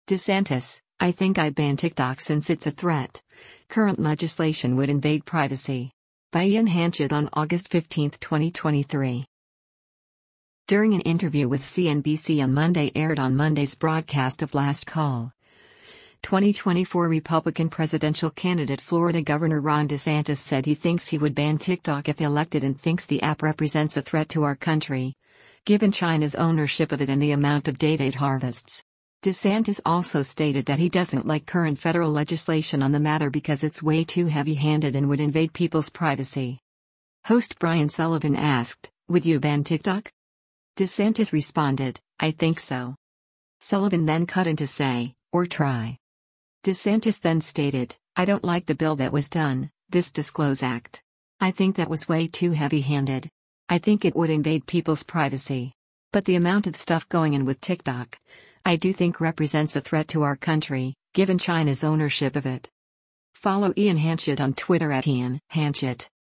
During an interview with CNBC on Monday aired on Monday’s broadcast of “Last Call,” 2024 Republican presidential candidate Florida Gov. Ron DeSantis said he thinks he would ban TikTok if elected and thinks the app “represents a threat to our country, given China’s ownership of it” and the amount of data it harvests. DeSantis also stated that he doesn’t like current federal legislation on the matter because it’s “way too heavy-handed” and “would invade people’s privacy.”